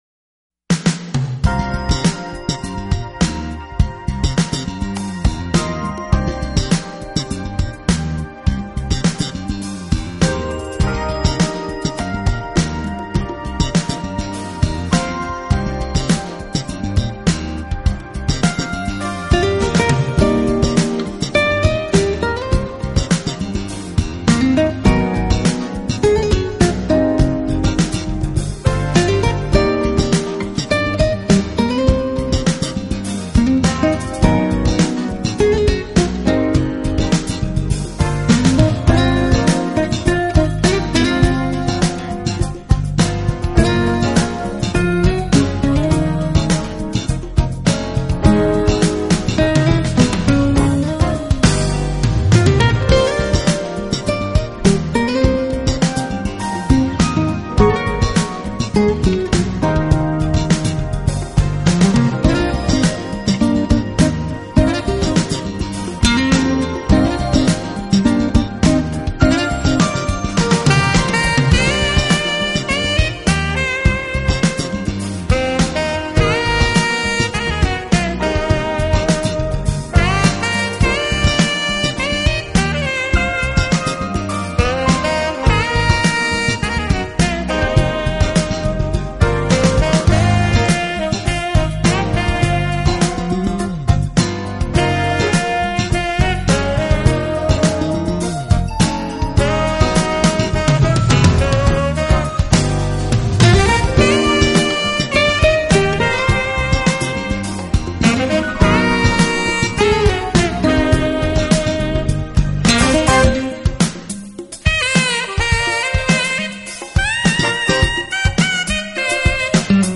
爵士吉他合集
一张精彩的爵士吉他合辑，荟萃了当今众多艺术名家，集爵士吉他之精华。